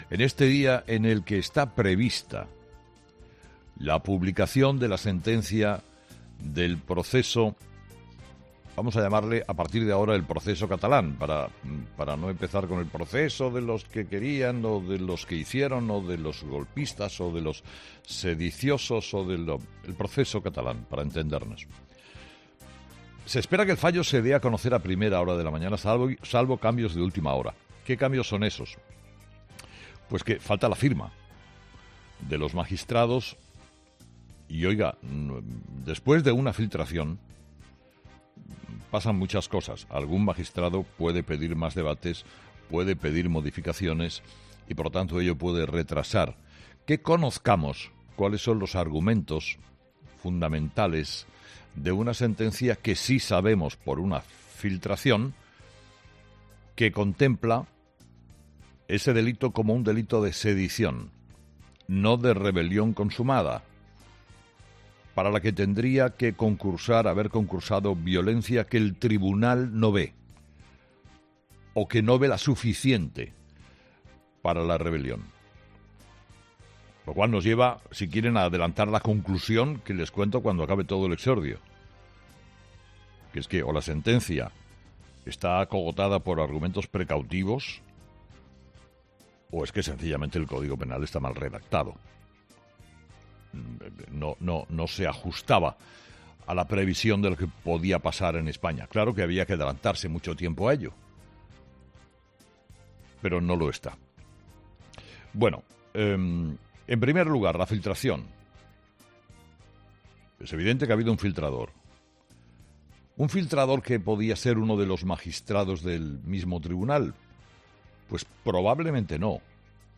Carlos Herrera ha dedicado su monólogo de las 06.00 a analizar la sentencia del Tribunal Supremo para los políticos presos independentistas. El comunicador ha analizado la filtración que especula con que finalmente tendrán penas por sedición y no por rebelión.